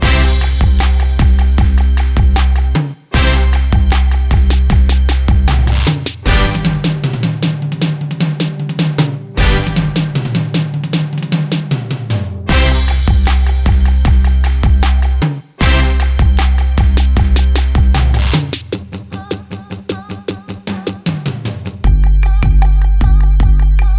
For Solo Female Pop (2010s) 3:54 Buy £1.50